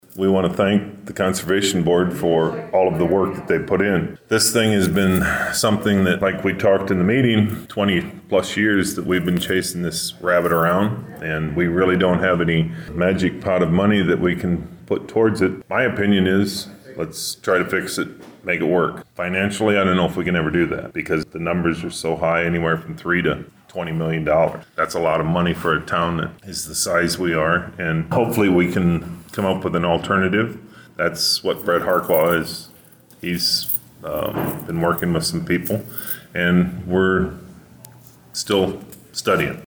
Board of Supervisors Chairman Bruce Reimers had this to say about the discussion on Monday about the Reasoner Dam Project.